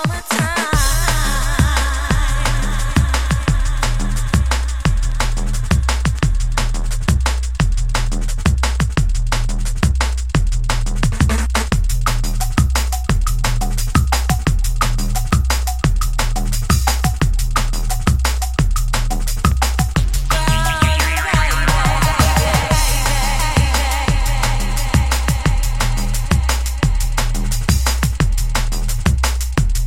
TOP > Deep / Liquid